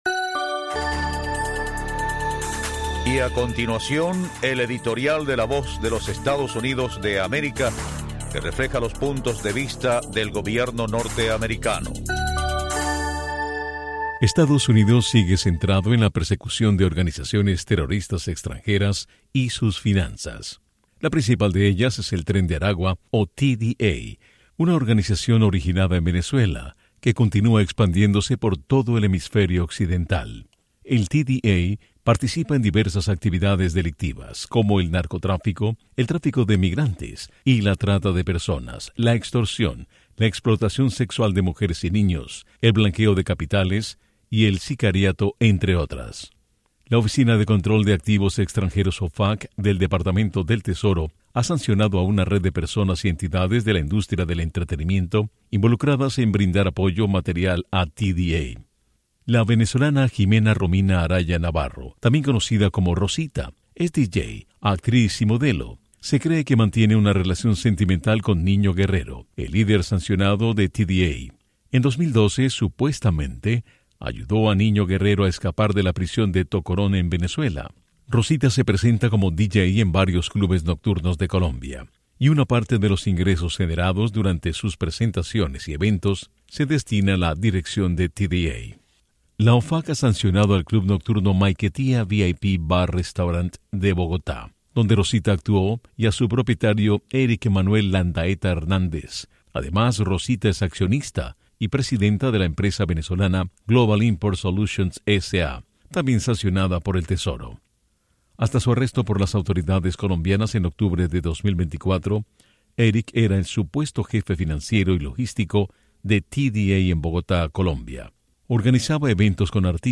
Editoriales